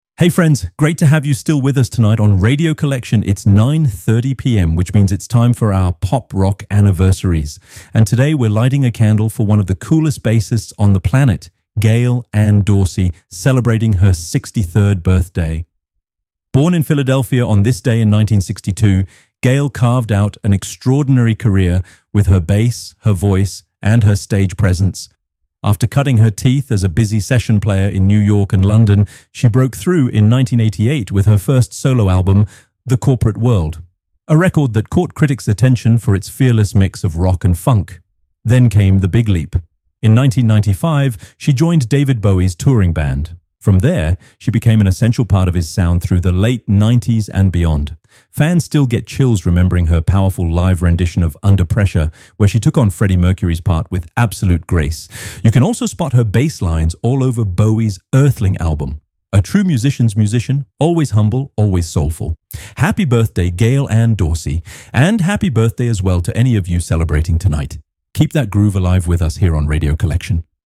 A lively column that mixes memories, anecdotes, and discoveries for a true daily journey through the history of Pop Rock fans' favorite artists, from the 70s to today.